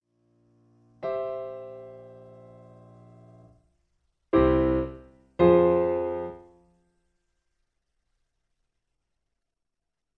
Semitone down (B). Piano Accompaniment